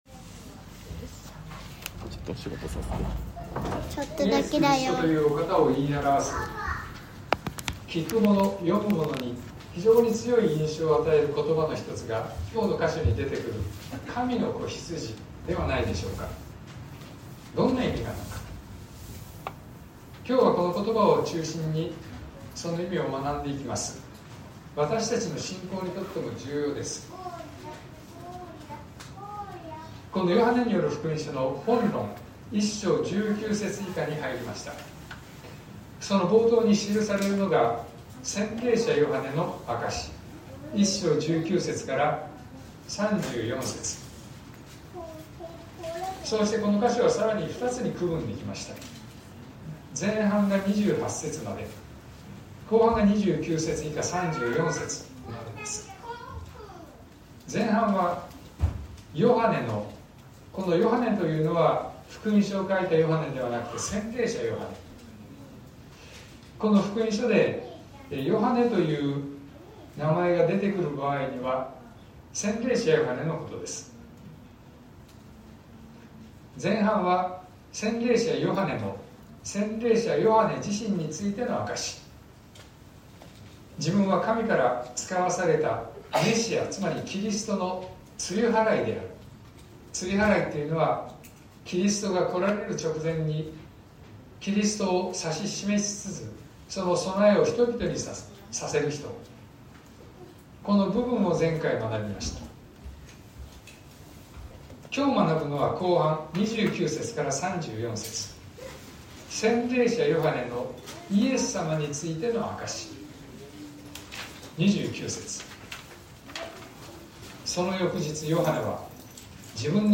2025年03月02日朝の礼拝「見よ、神の子羊」東京教会
説教アーカイブ。